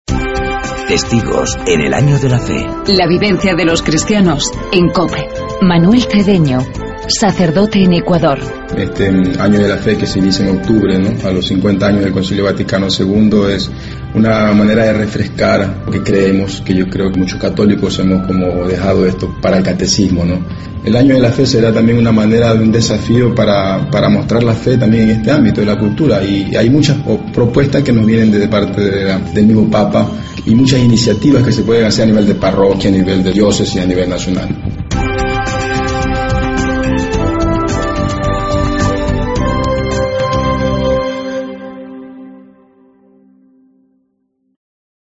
Hoy escuchamos el testimonio del sacerdote ecuatoriano